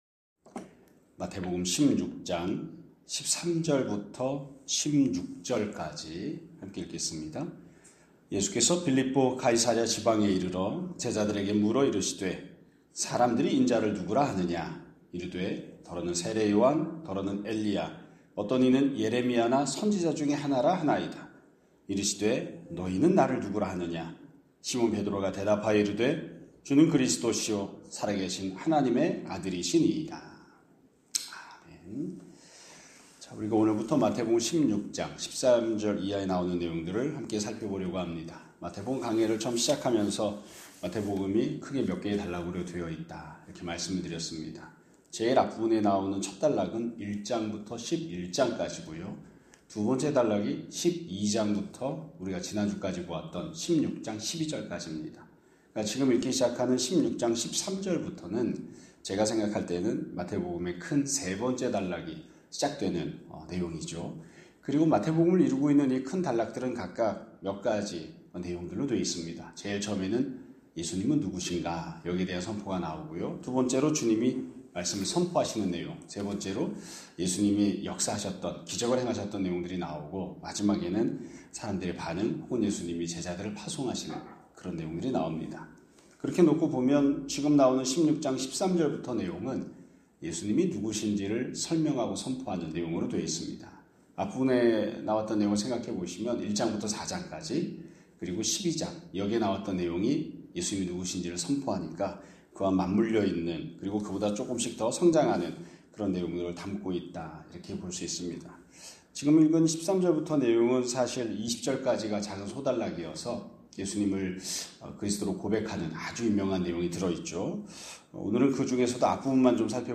2025년 11월 17일 (월요일) <아침예배> 설교입니다.